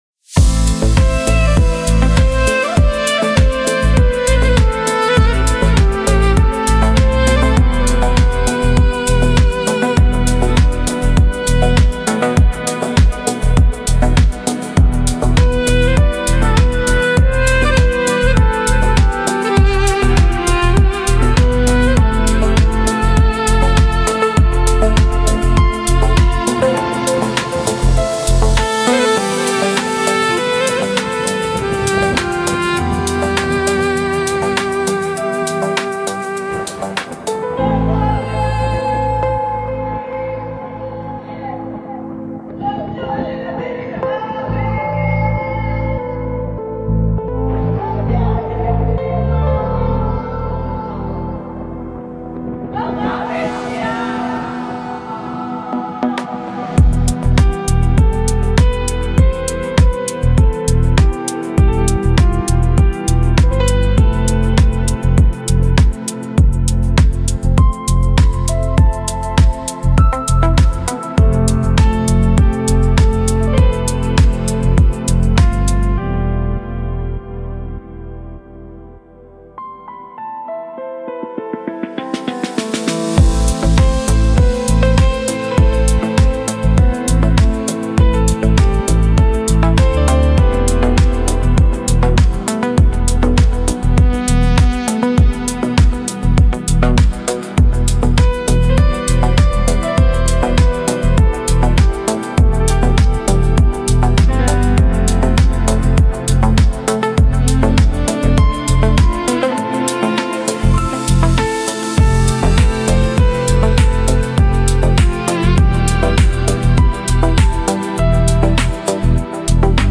آهنگ بی‌کلام غمگین